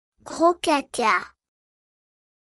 \Grow-CAh-Cah\